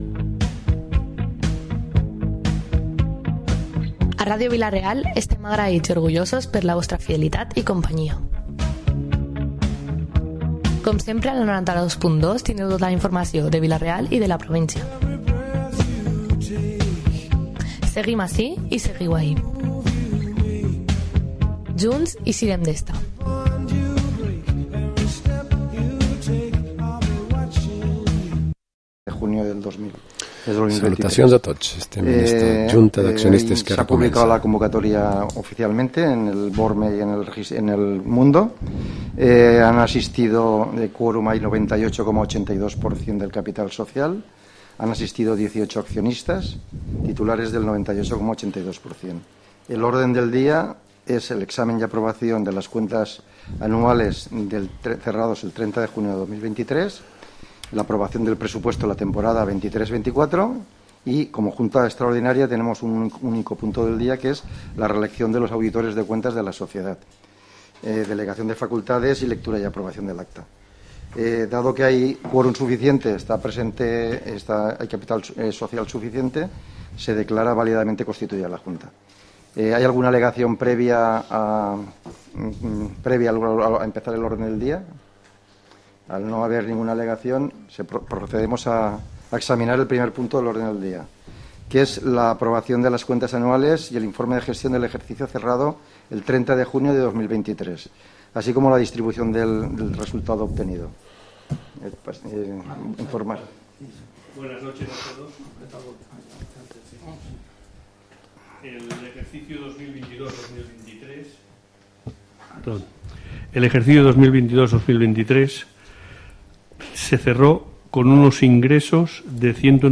Programa esports tertúlia dilluns 18 de Desembre